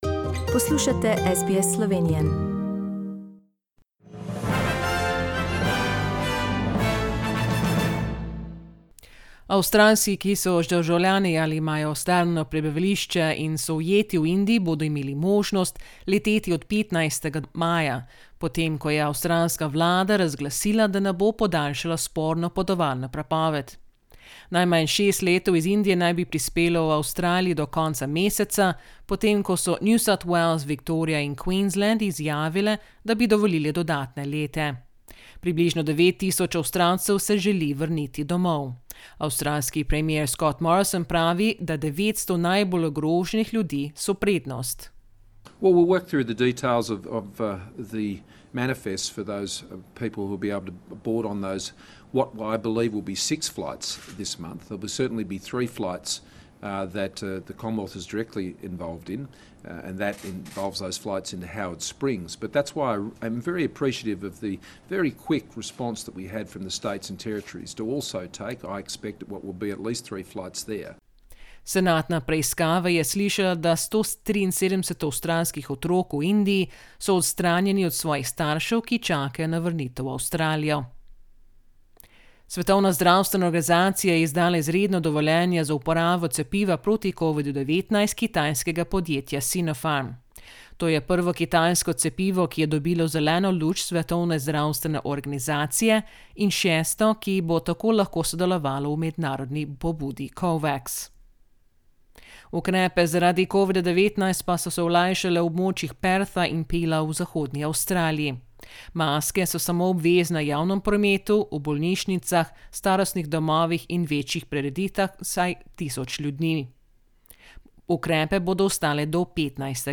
Listen to the latest news headlines in Australia from SBS Slovenian radio.